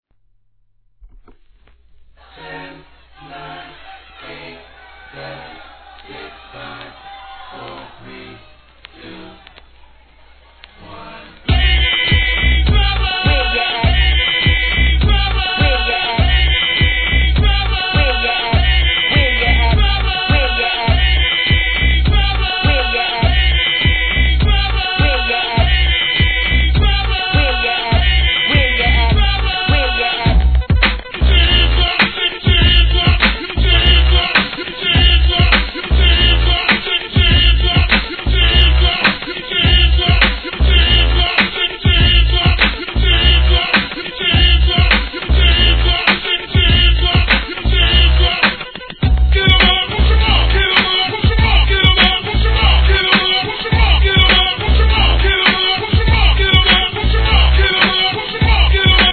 HIP HOP/R&B
PARTYトラックの王道的一枚！